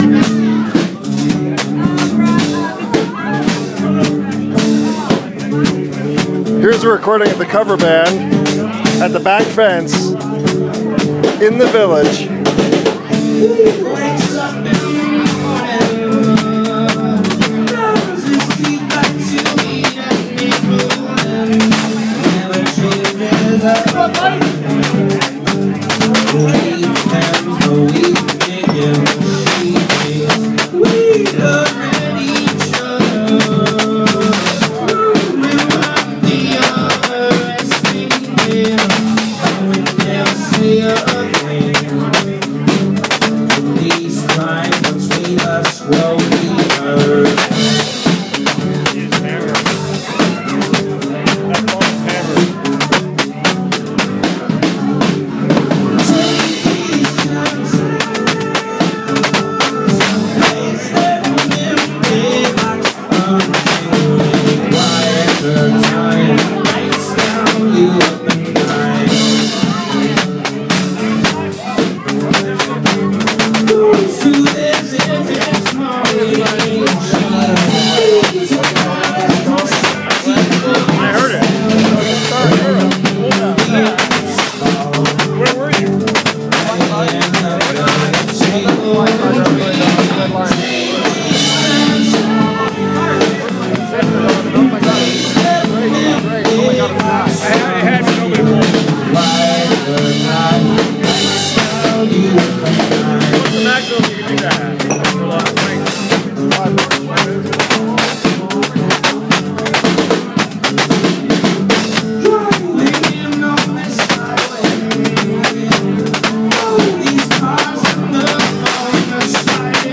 The cover band at the Back Fence. Really good: